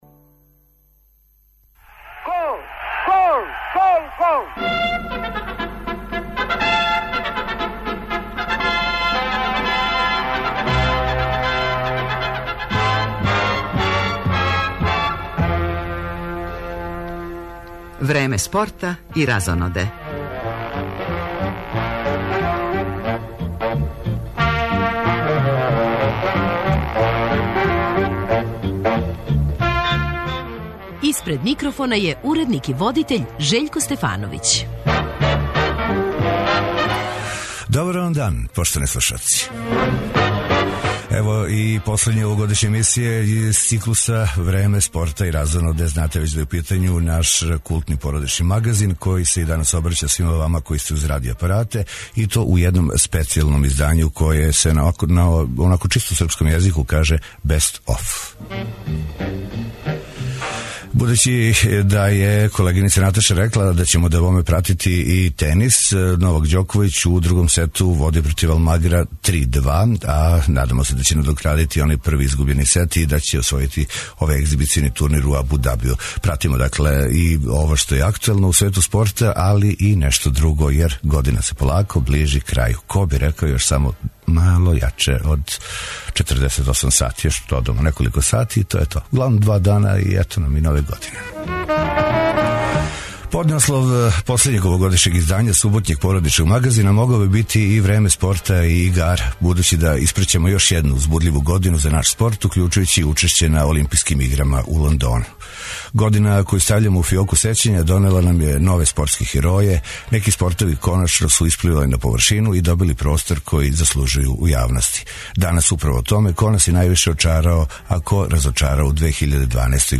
Поднаслов последњег овогодишњег издања суботњег породичног магазина могао би бити и Време спорта и игара, будући да испраћамо још једну узбудљиву годину за наш спорт, укључујући и учешће на Олимпијским играма у Лондону.